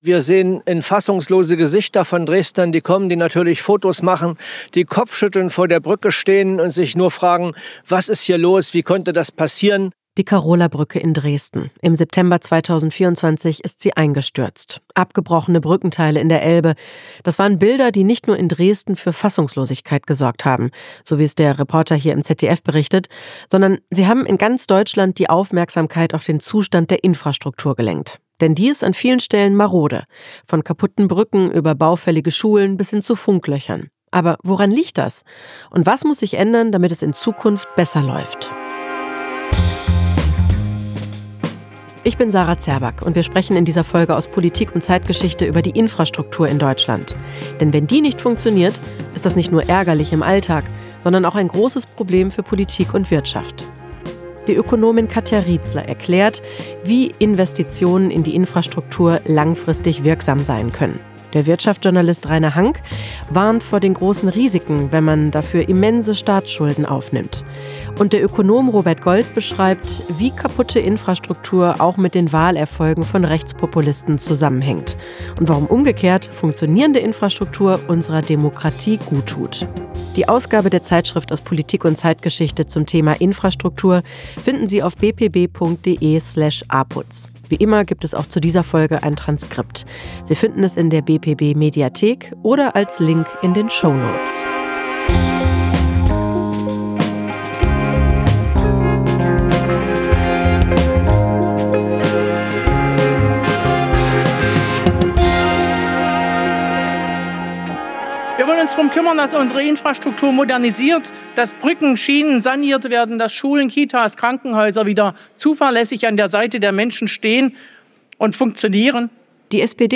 Doch welche Veränderungen sind besonders dringend? Und wie könnten sich Verbesserungen auf die Wahlergebnisse populistischer Parteien auswirken? Fragen an die Ökonomen